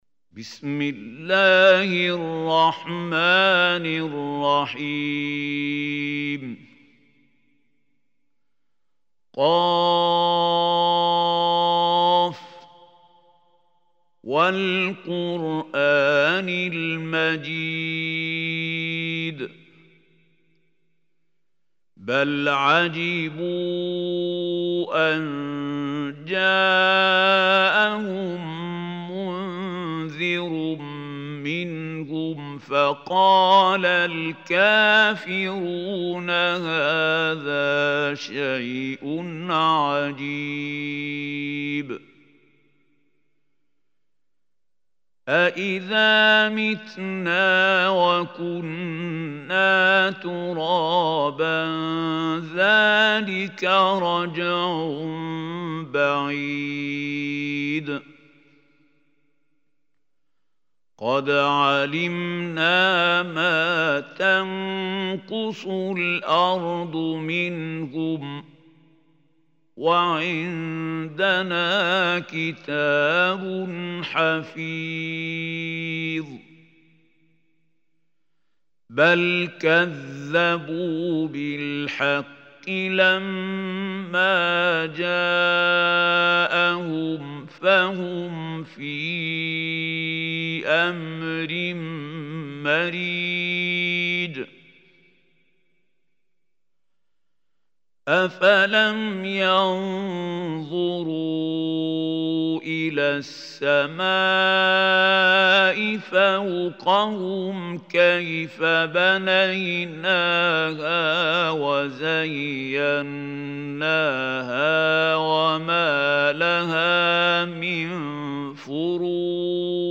Surah Qaf Recitation by Mahmoud Khalil Hussary
Surah Qaf is 50 surah of Holy Quran. Listen or play online mp3 tilawat / recitation in Arabic in the beautiful voice of Sheikh Mahmoud Khalil Al Hussary.